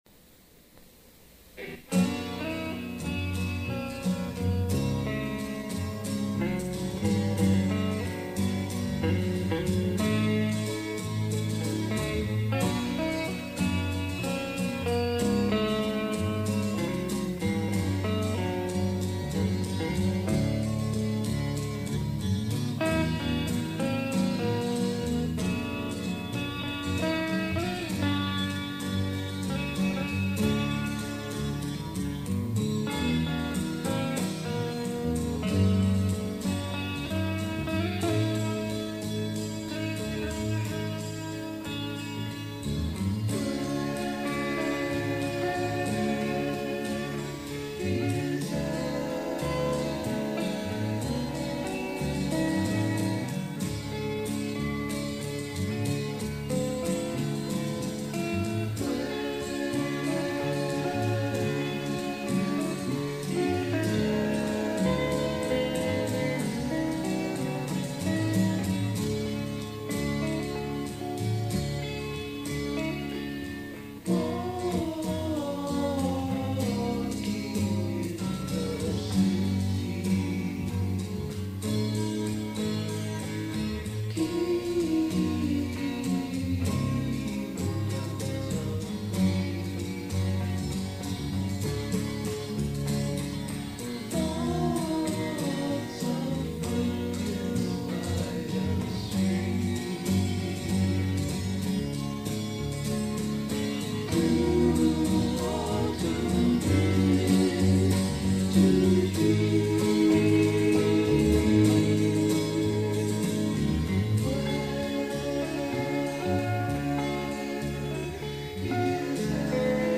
basement
sing together in beautiful harmony.